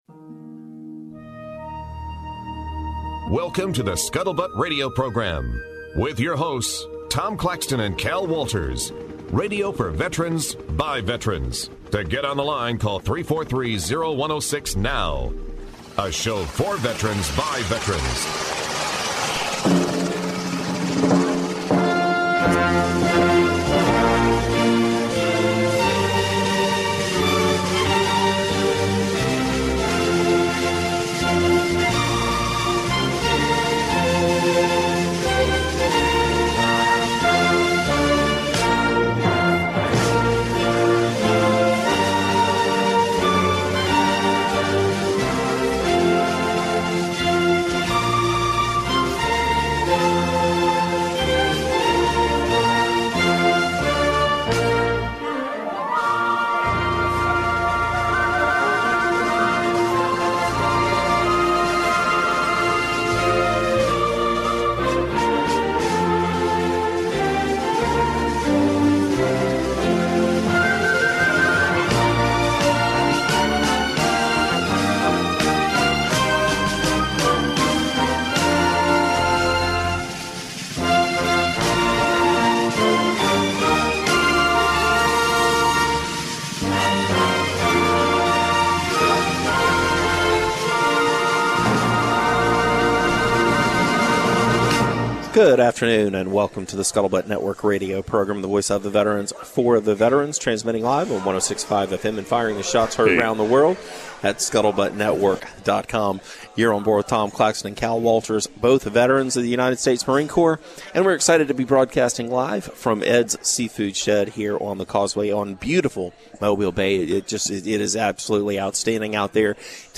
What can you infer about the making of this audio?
Today's show originates from Ed's Seafood Shed on the Causeway in Mobile.